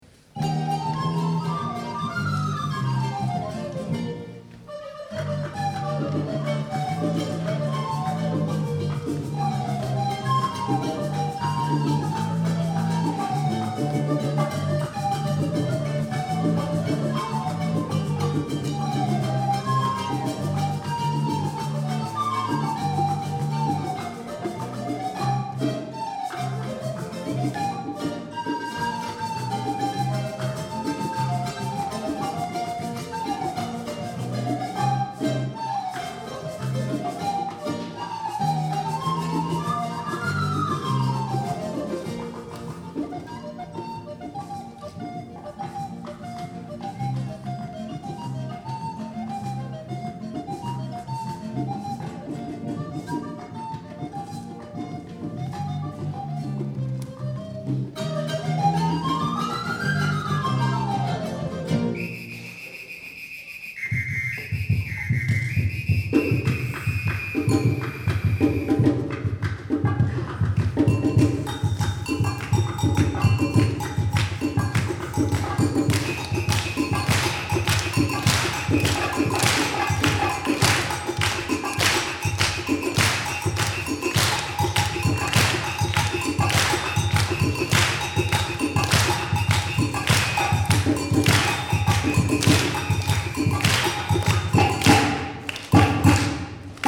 Inti, Blokkfløytistene Uranienborg.
Fra konserten "Musica Entre Culturas" i Gamle Logen, Oslo 8. juni 1997.